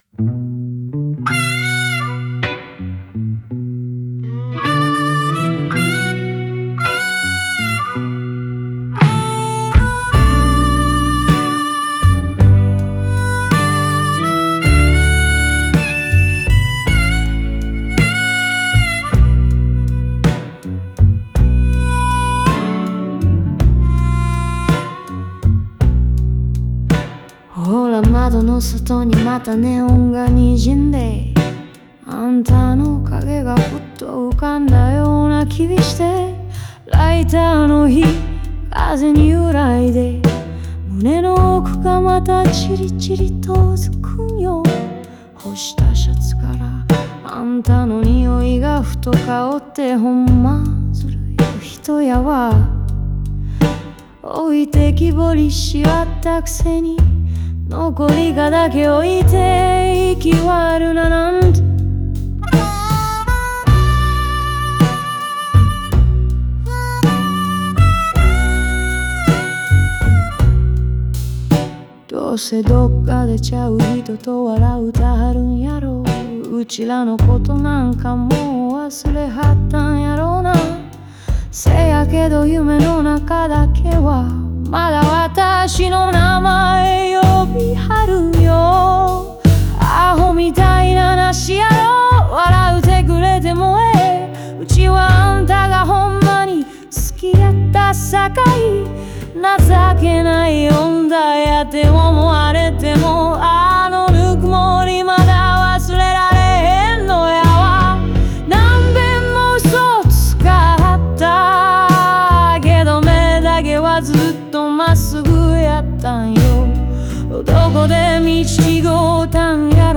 スライドギターやハーモニカの哀愁を帯びた音色が、彼女の声と相まって、土臭くも都会的なブルースの世界を表現。
京都弁のはんなりとした響きが、哀愁の中に優雅さを添え、聴く者の心に深く響く情感豊かな歌詞である。